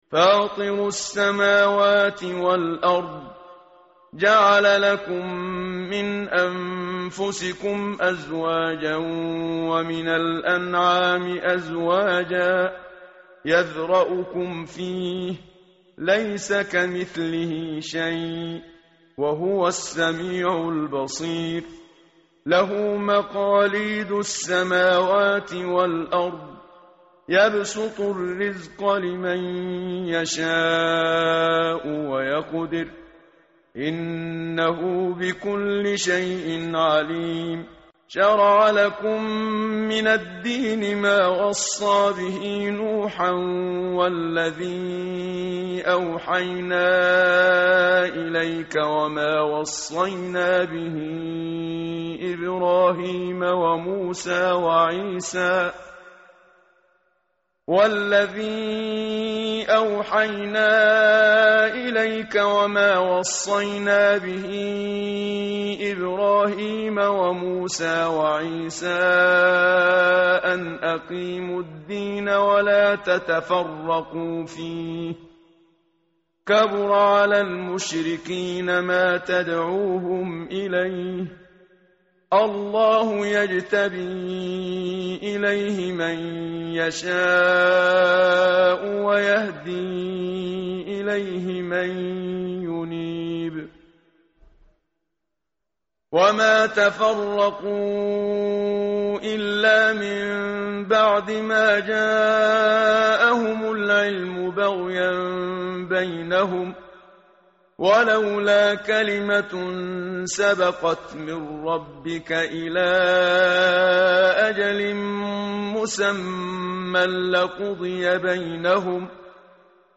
tartil_menshavi_page_484.mp3